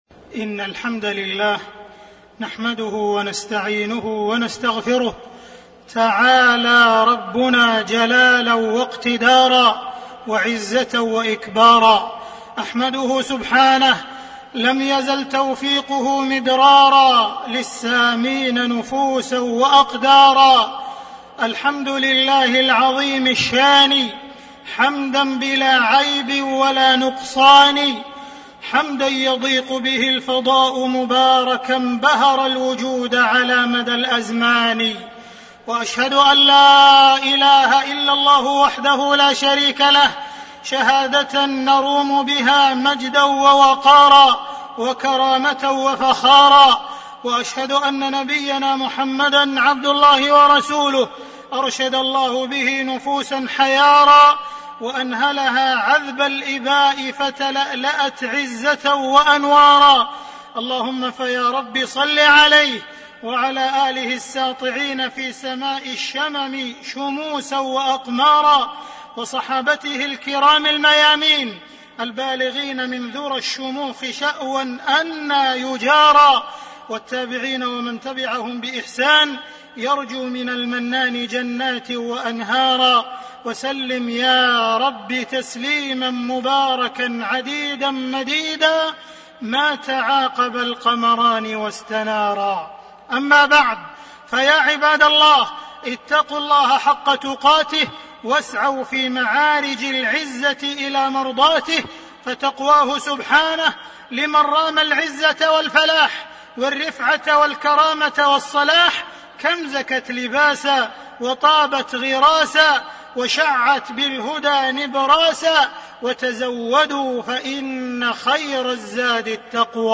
تاريخ النشر ١٣ جمادى الآخرة ١٤٣٣ هـ المكان: المسجد الحرام الشيخ: معالي الشيخ أ.د. عبدالرحمن بن عبدالعزيز السديس معالي الشيخ أ.د. عبدالرحمن بن عبدالعزيز السديس قيمة العزة والكرامة